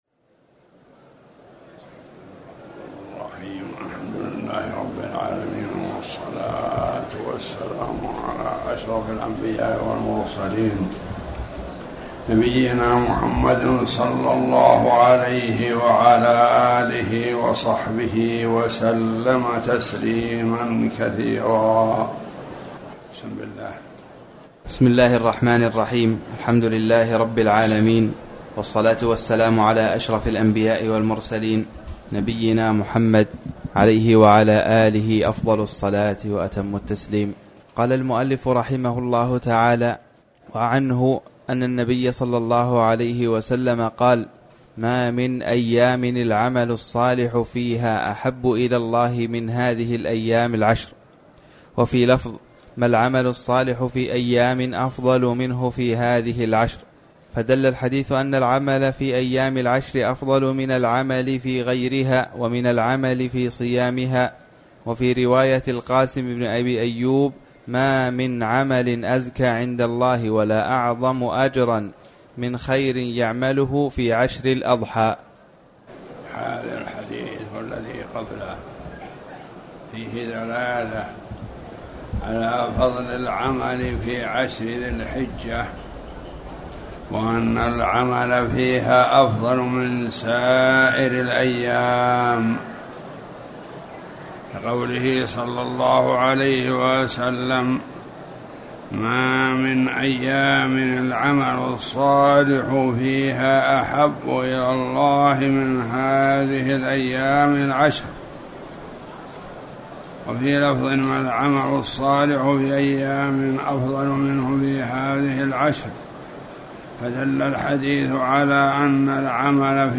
تاريخ النشر ٢٢ ذو القعدة ١٤٣٩ هـ المكان: المسجد الحرام الشيخ